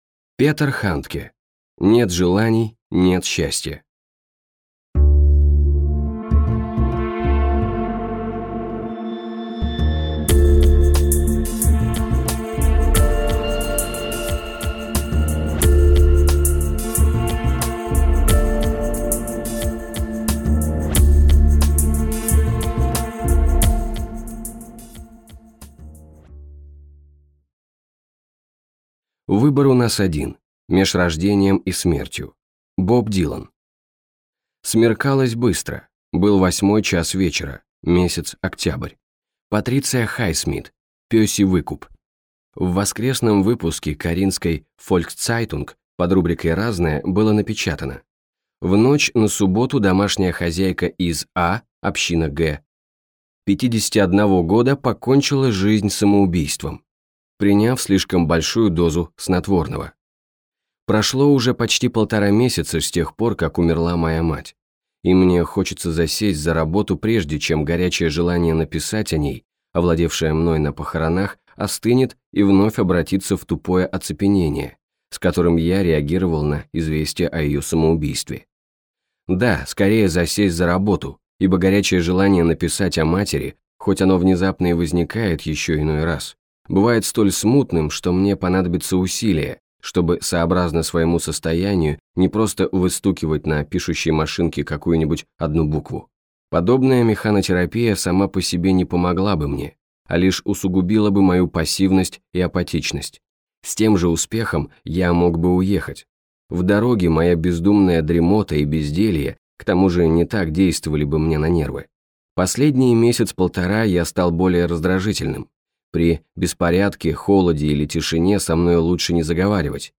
Аудиокнига Нет желаний – нет счастья | Библиотека аудиокниг
Прослушать и бесплатно скачать фрагмент аудиокниги